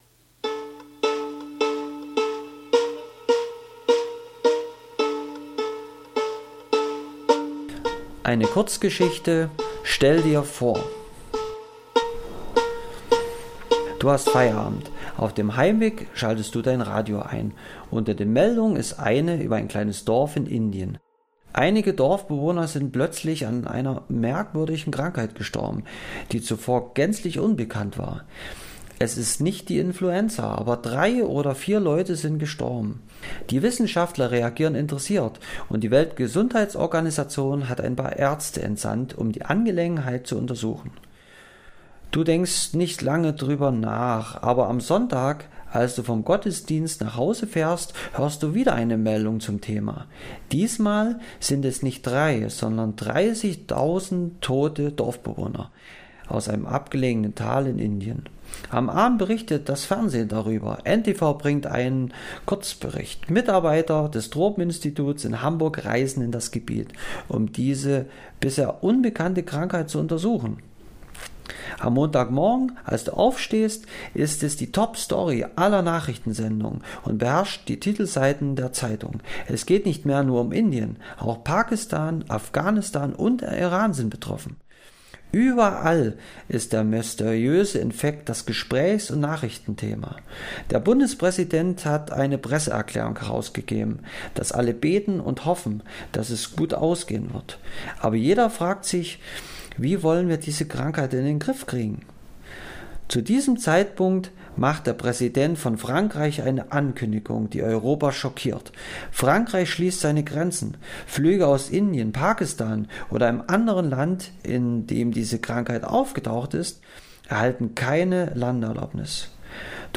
vortraege:hoerbuch-kurzgeschichte.mp3